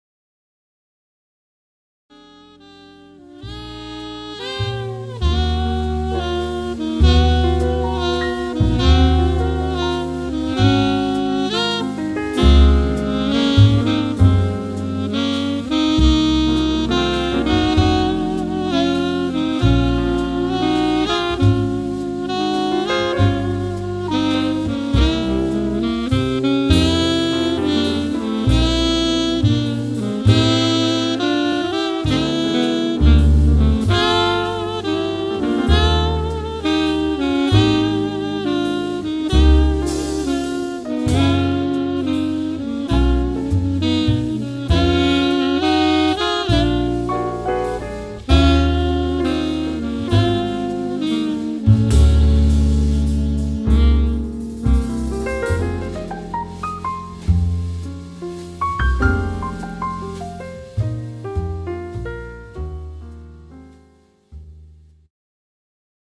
The Best In British Jazz
Recorded at Clowns Pocket Studio, London 2014